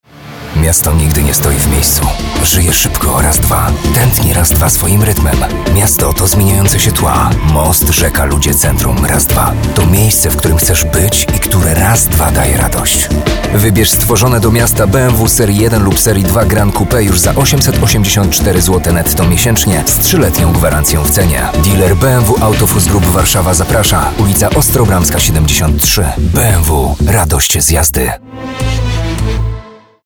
Mężczyzna 30-50 lat
Głos lektora charakteryzuje się głęboką, ciepłą alikwotyczną barwą. Potrafi on modulować ton i tempo mowy, dostosowując się do kontekstu - od dynamicznych reklam i zwiastunów po spokojniejsze, bardziej refleksyjne treści. Dzięki doświadczeniu scenicznemu interpretacja tekstu jest naturalna i przekonująca a dykcja klarowna.
Spot reklamowy